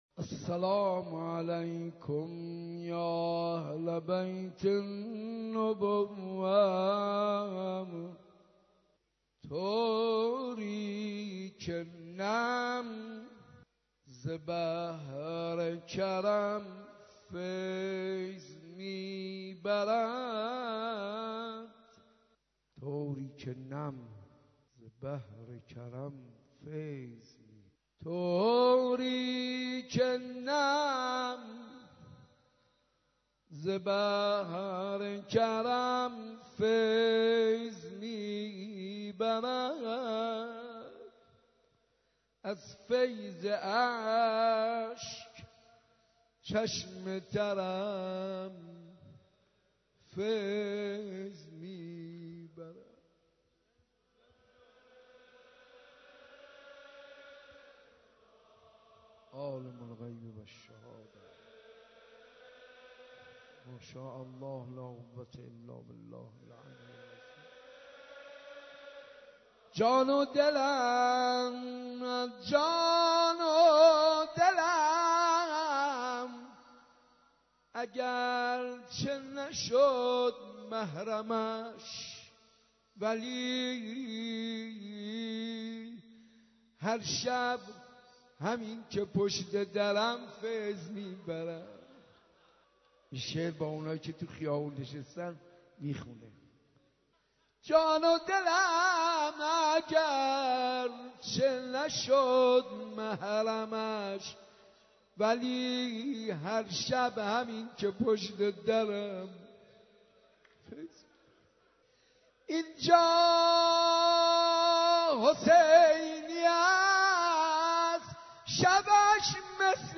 (تا شب هشتم با کیفیت بالا اضافه شد)